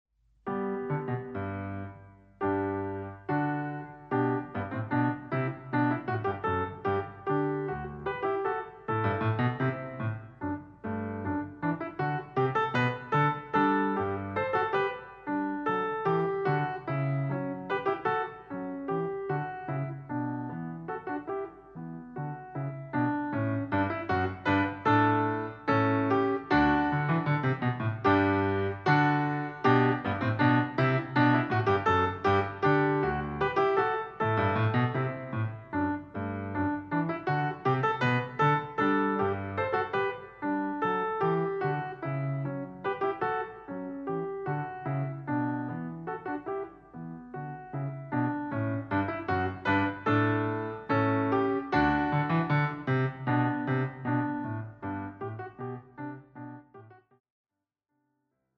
Voicing: Cello and Online Audio